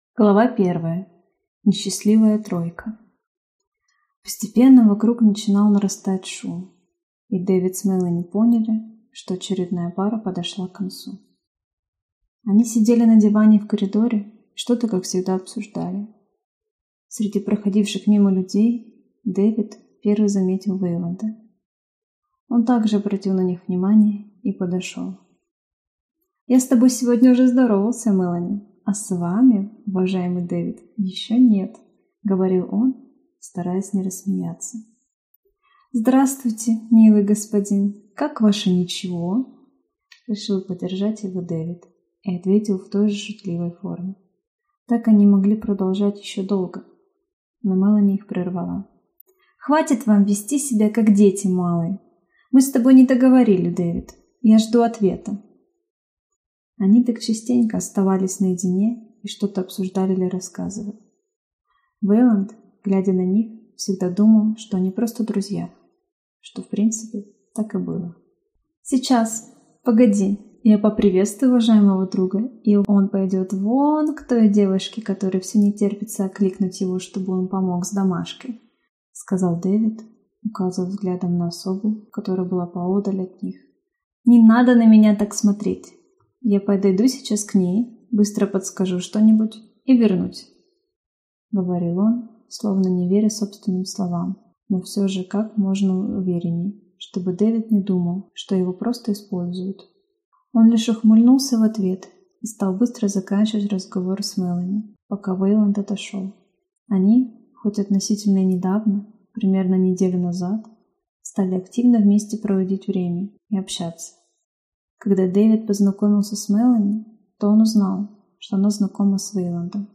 Аудиокнига Лунные тени. Параллельно задавая вопрос | Библиотека аудиокниг